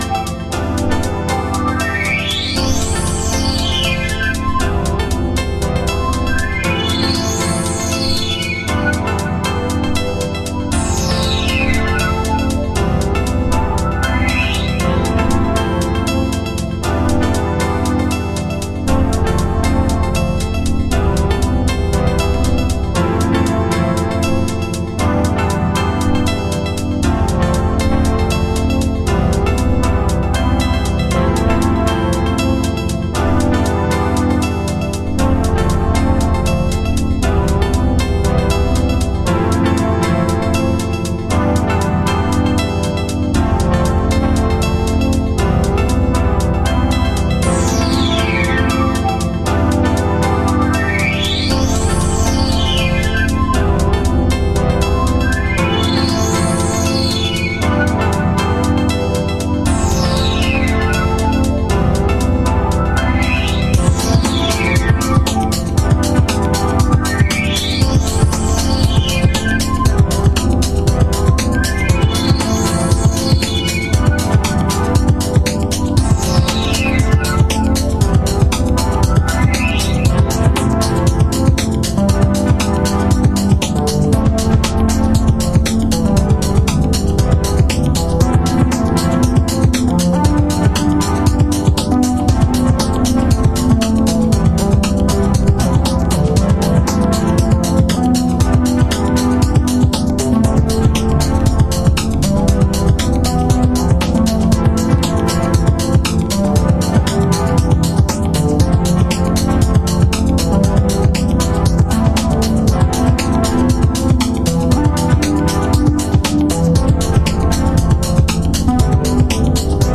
エクスタティックな空間系シンセが揺らめく4トラック。ブレイクビーツを組み込んだB2が当店好み。